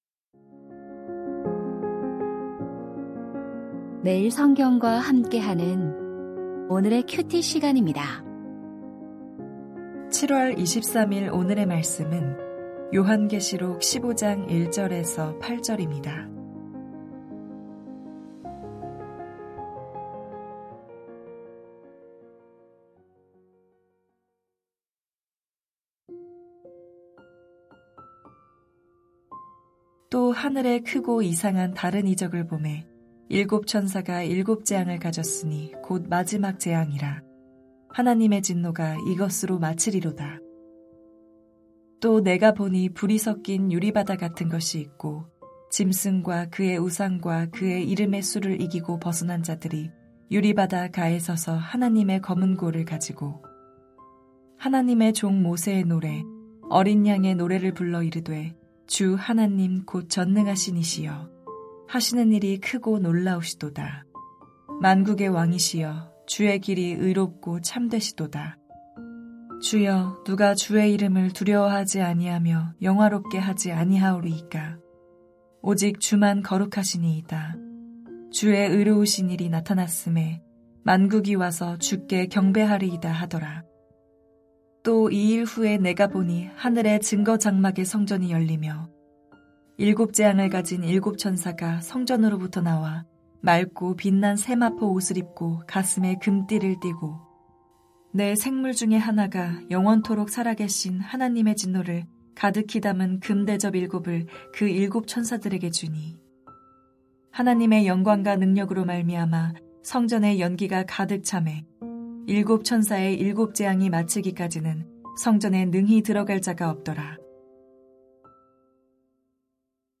* 설교